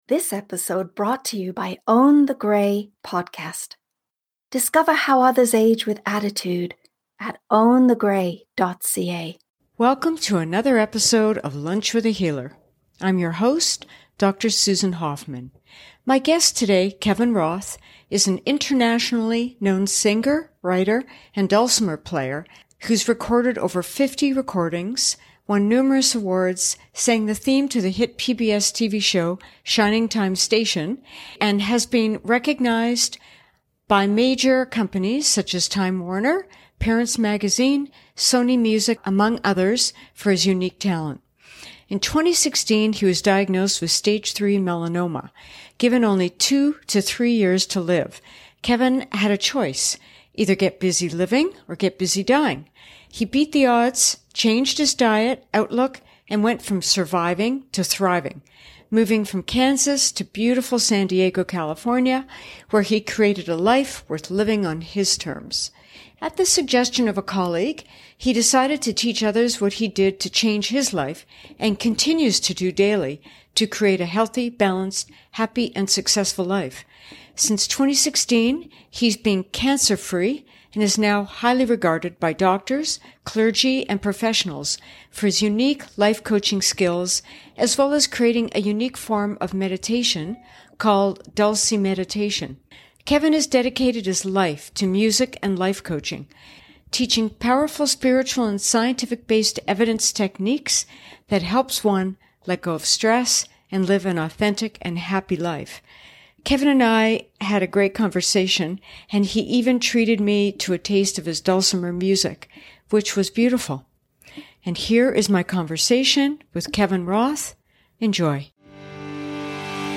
Dulcimer